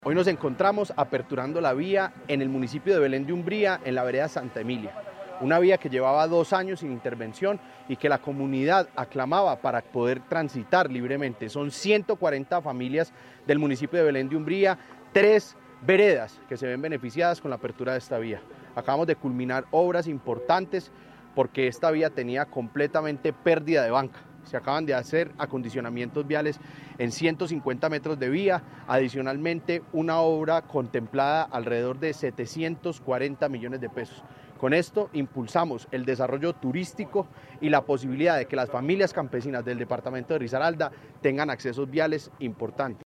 JUAN-DIEGO-PATINO-OCHOA-GOBERNADOR-RISARALDA.mp3